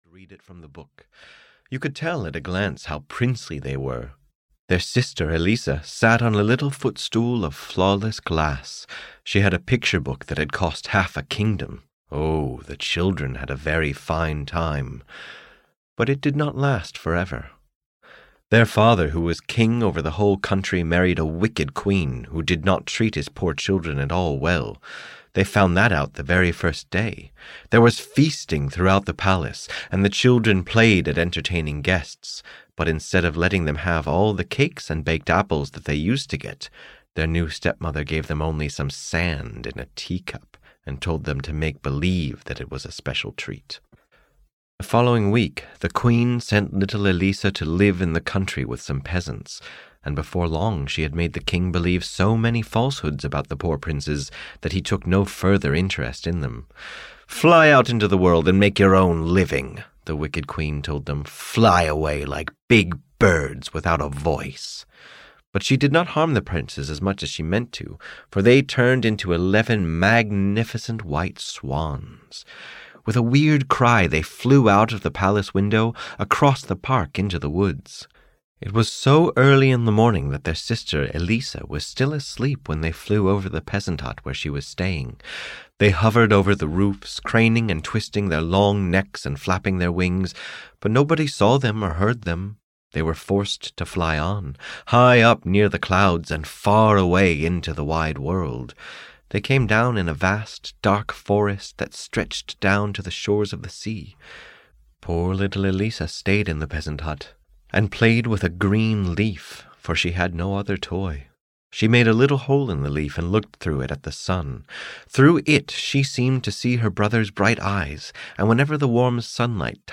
The Wild Swans (EN) audiokniha
Ukázka z knihy